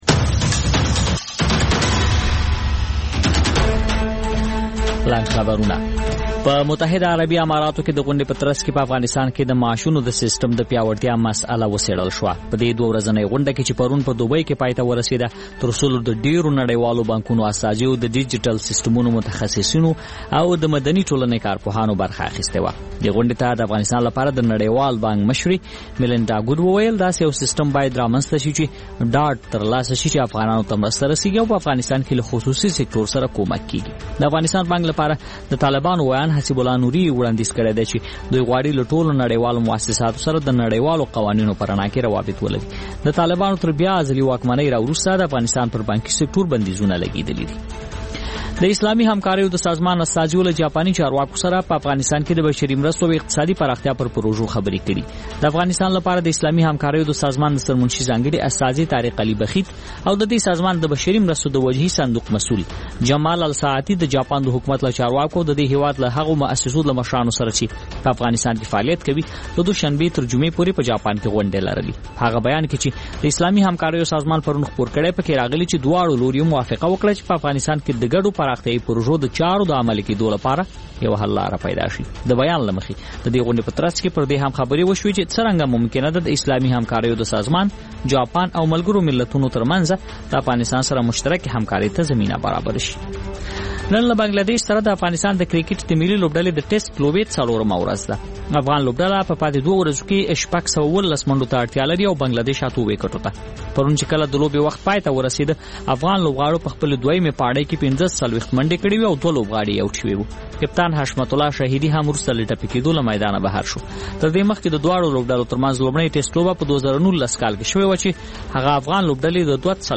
خبرونه او راپورونه